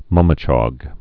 (mŭmĭ-chŏg)